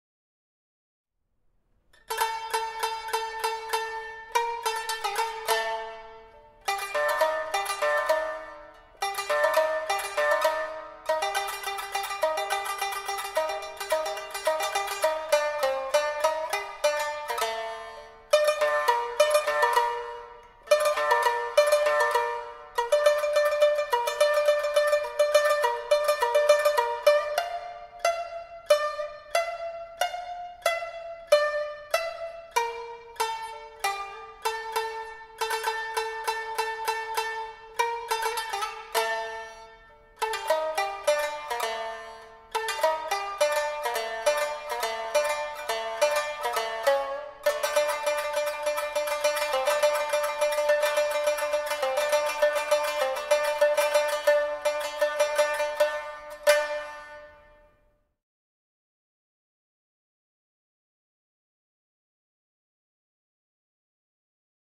Настоящий инструмент в действии